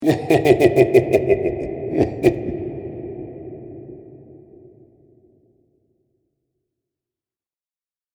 Carnevil_Evil_Laughter.mp3